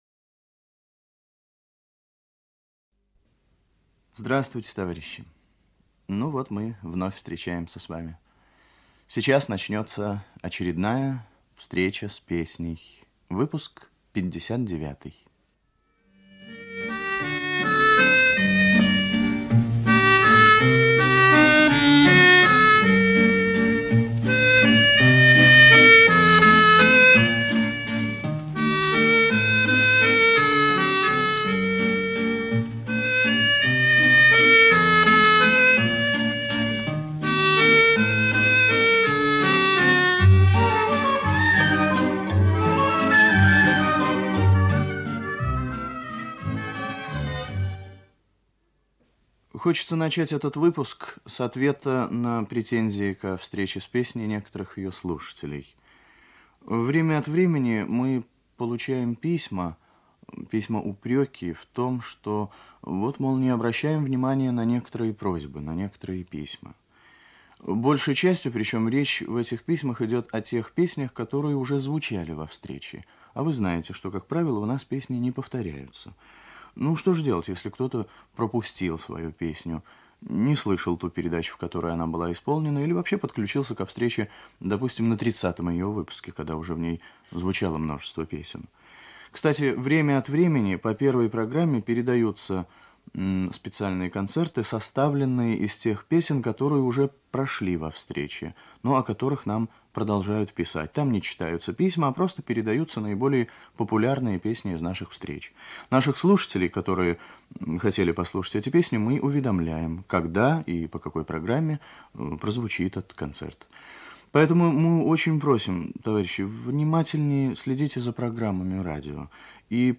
Старинный русский романс
Песня гражданской войны
гитара